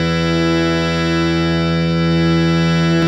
52-key02-harm-e2.wav